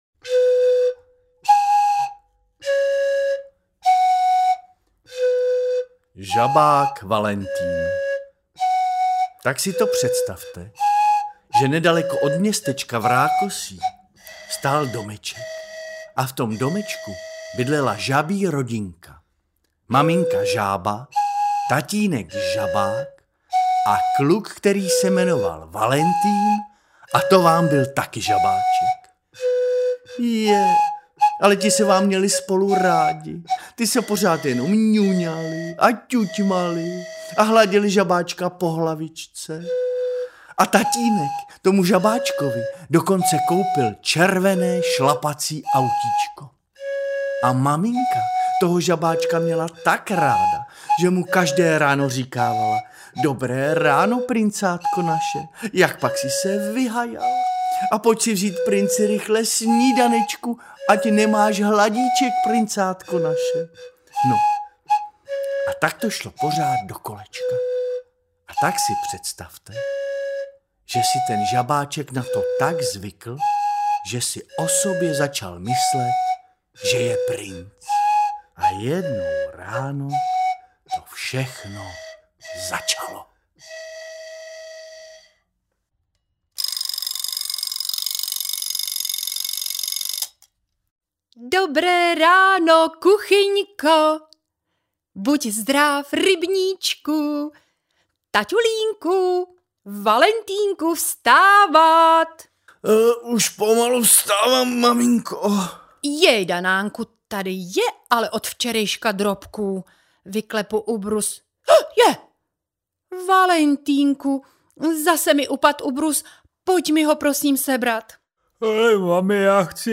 HUKOŽA PÁÁ audiokniha
NÁKUPEM AUDIOKNIHY PODPOŘÍTE BUCHTY A LOUTKY Pohádkový soubor "HUKOŽA PÁÁ" je audio úprava dětských představení Buchet a loutek.
Ukázka z knihy
• InterpretBuchty a loutky